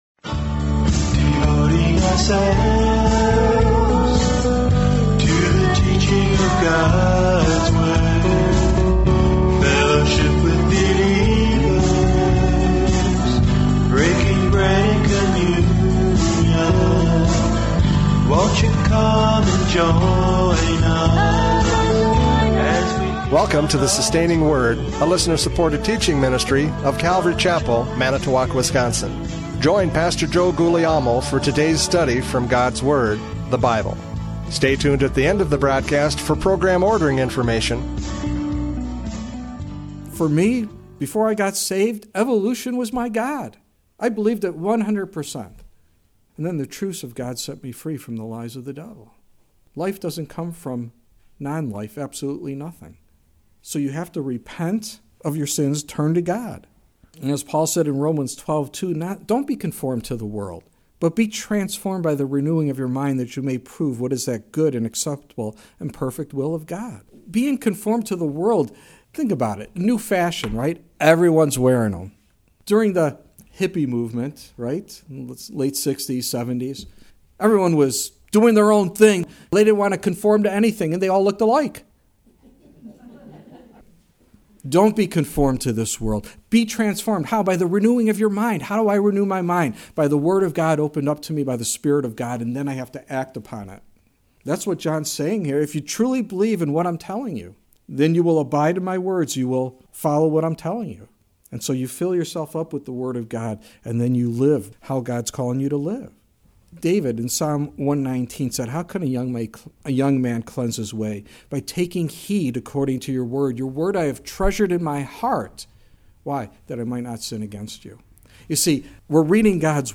John 8:31-38 Service Type: Radio Programs « John 8:31-38 Freedom in Christ!